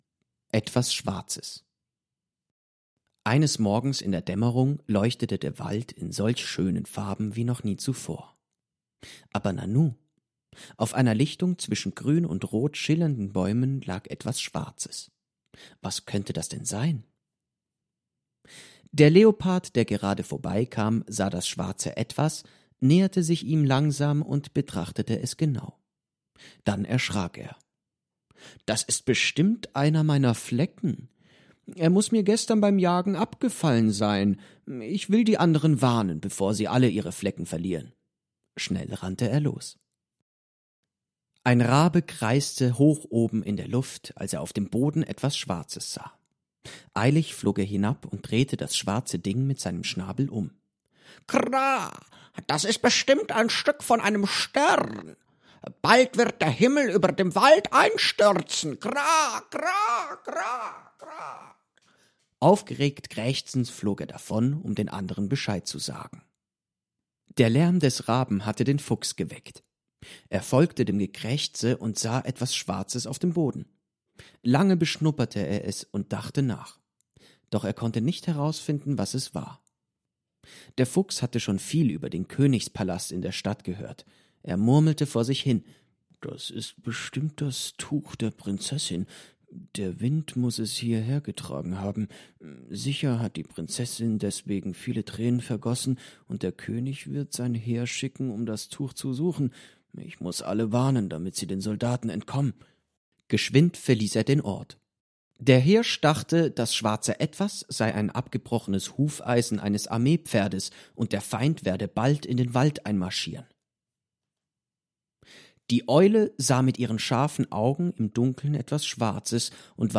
→ Mehr zum BuchWeitere Materialien zum Buch→ Buchtext Persisch (PDF)→ Lesung Persisch (MP3)→ Lesung Deutsch (MP3)→ Leitfaden Philosophisches Gespräch (PDF)→ Angebot Leseanimation «BuchBesuch»